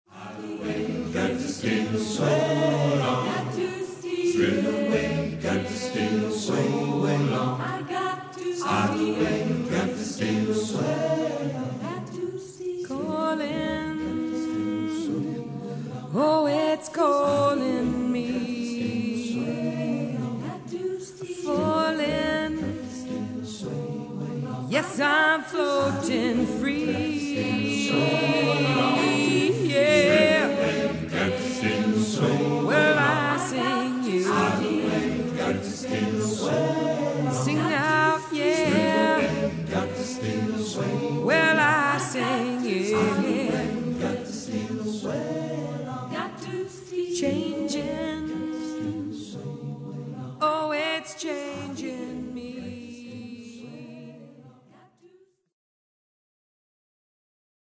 This song is included on the choir's first, self-titled CD